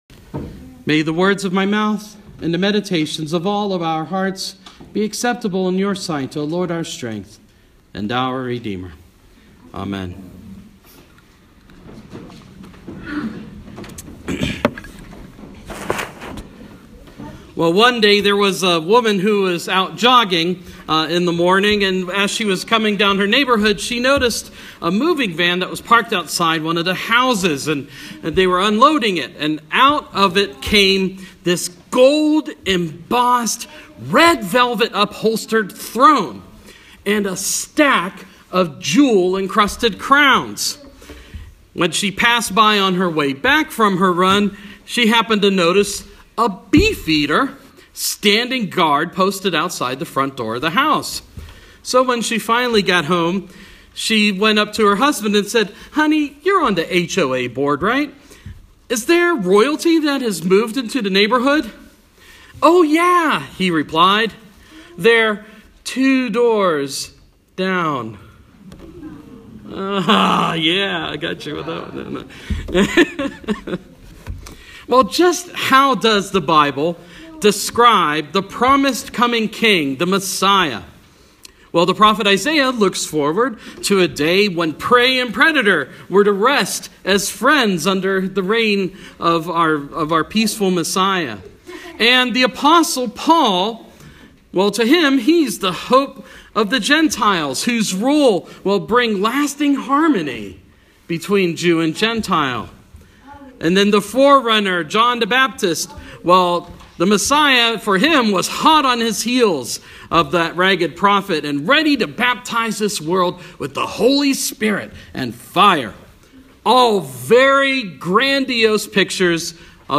Sermons -Second Sunday in Advent – 2016